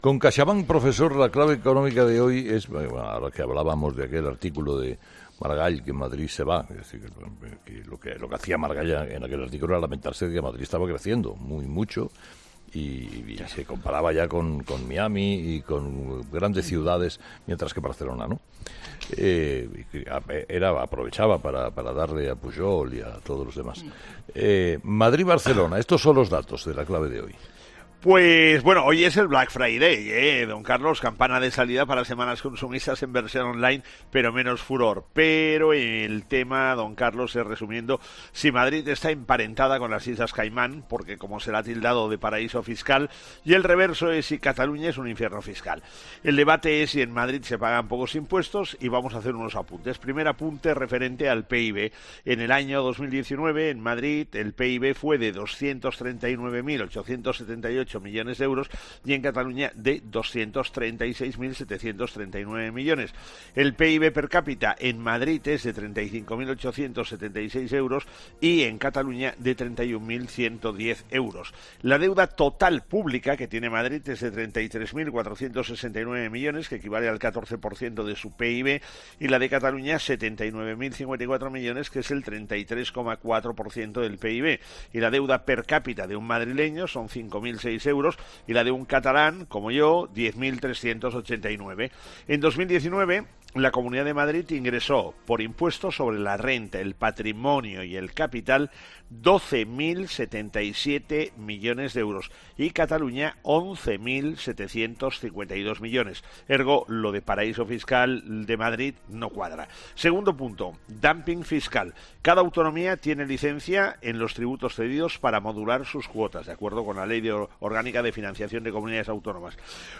El profesor José María Gay de Liébana analiza en'Herrera en COPE’ las claves económicas del día.